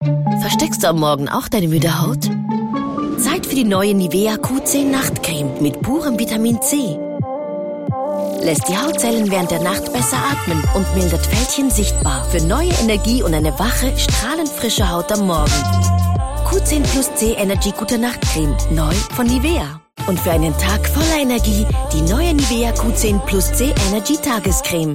Werbung Hochdeutsch (CH)
Schauspielerin mit breitem Einsatzspektrum.